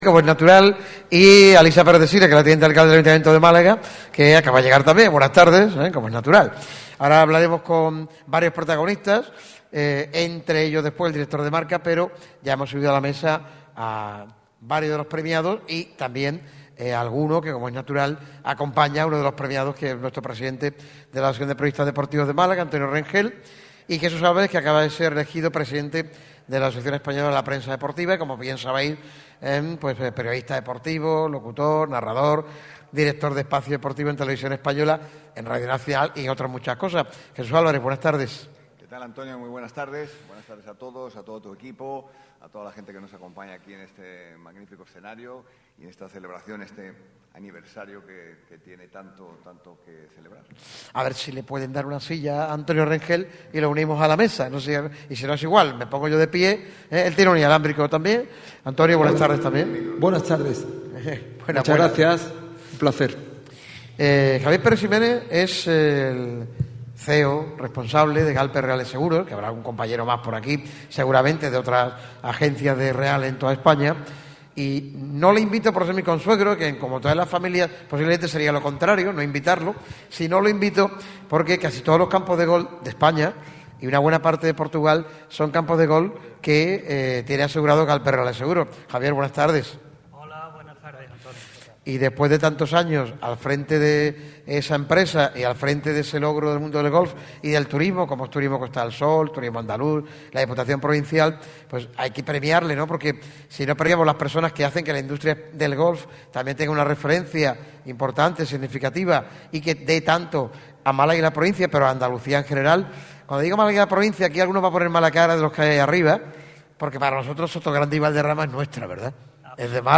La emisora líder del deporte en Málaga está nuevamente de cumpleaños y no de uno cualquiera, sino de sus particulares bodas de plata. 25 años repartiendo información, debate, opinión y pasión con el deporte de la provincia costasoleña. Por ello, el micrófono rojo lo celebrará junto a numerosos invitados y protagonistas de excepción en el ya habitual y tradicional escenario del Auditorio Edgar Neville de la Diputación de Málaga. Una mañana de sorpresas, regalos y celebración en un evento que promete no dejar indiferente a nadie.